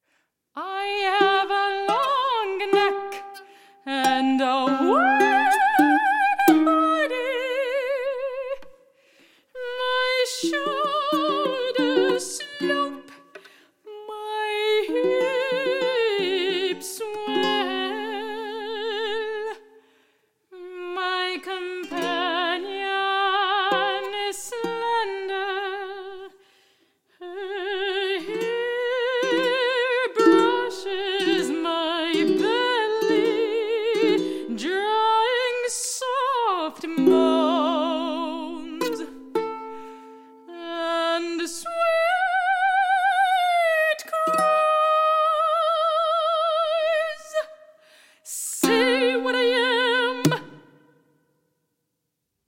• Genres: Classical, Opera
soprano & violin
soprano & ukulele